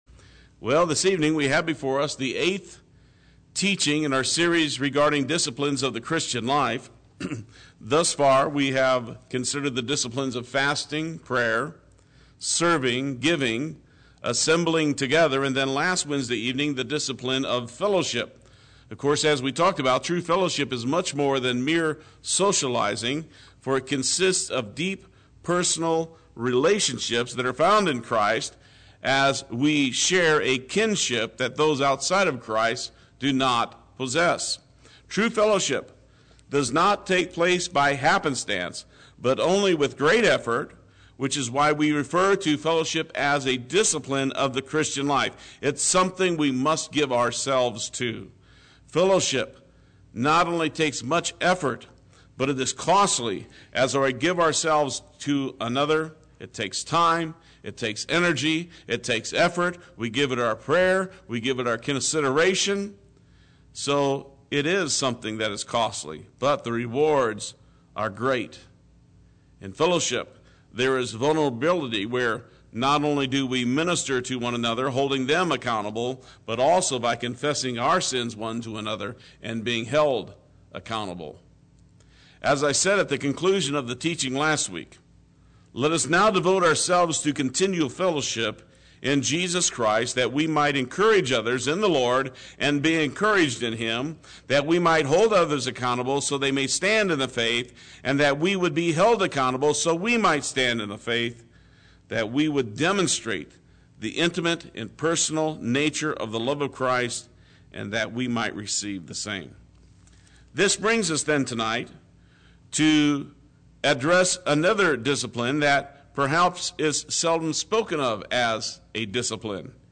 Play Sermon Get HCF Teaching Automatically.
Wednesday Worship